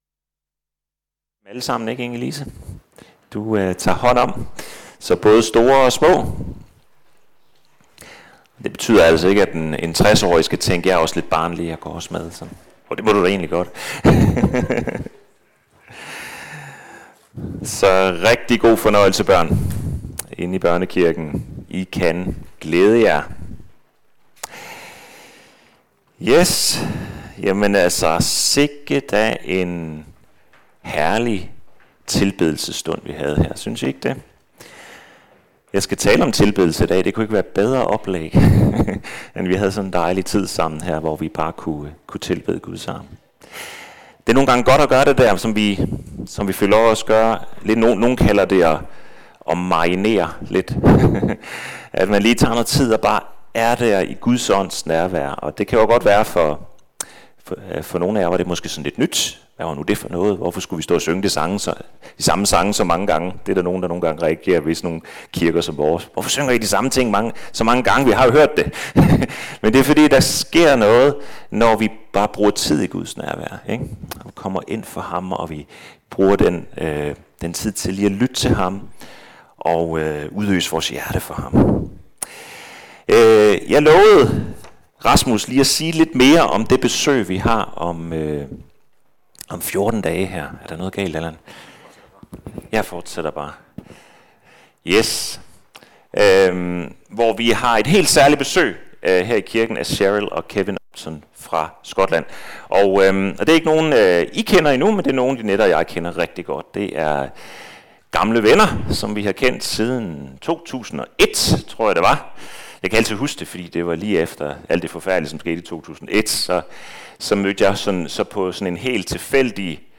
Series: Prædikener fra Tønder Frikirke
Service Type: Gudstjeneste